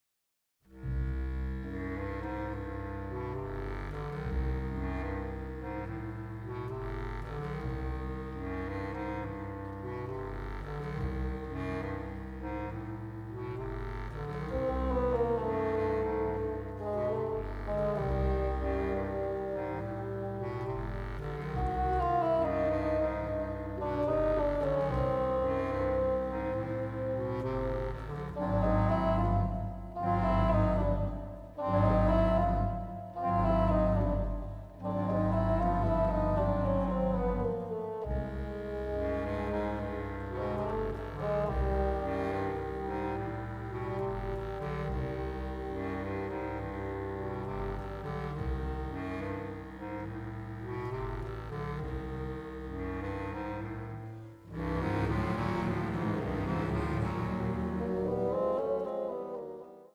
Recorded at CTS Studios in London